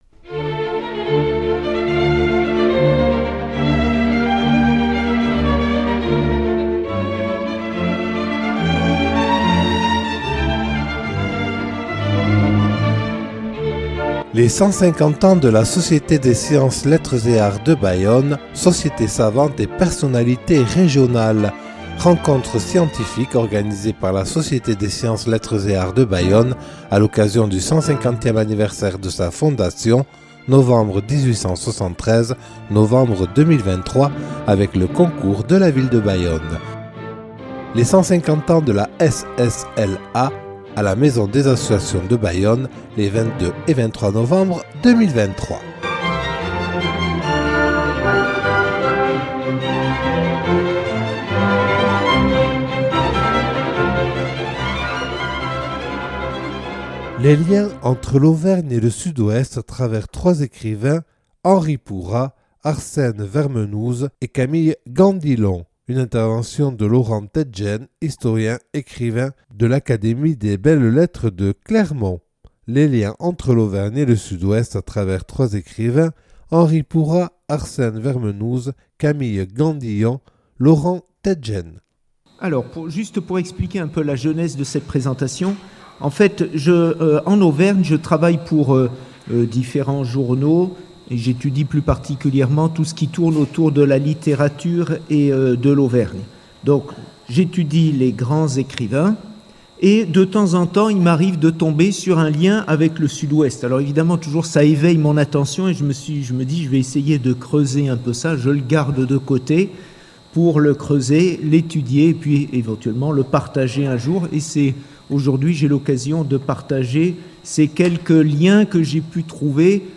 Les 150 ans de la Société des Sciences, Lettres et Arts de Bayonne – (9) – Rencontre scientifique des 22 et 23 novembre 2023